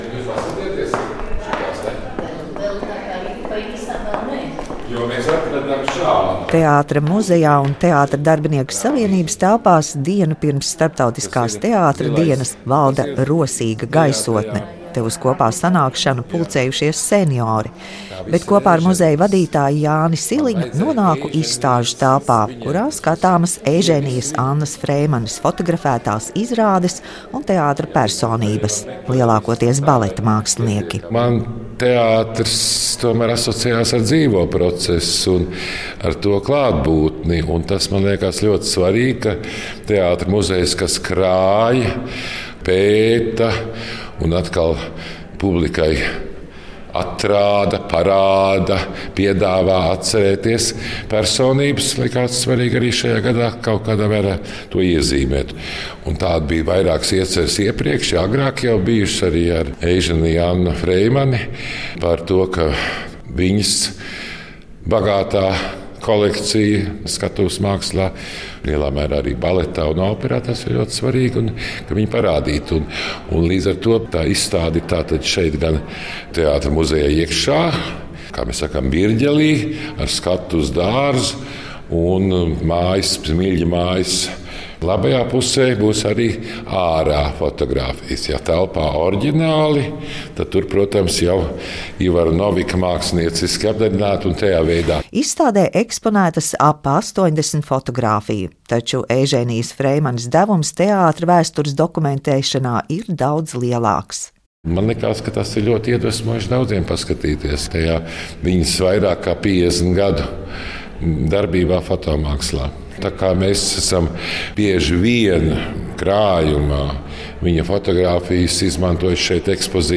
Pirmais valodfestivāls "Avots" aicina paskatīties uz valodas jautājumiem plašāk. Janvāra pēdējā dienā saruna par kultūras izcēlumiem gada pirmajā mēnesī.
Mēs ne tikai palīdzam orientēties kultūras notikumos, bet tiešraides sarunās apspriežam kultūras notikumu un kultūras personību rosinātas idejas.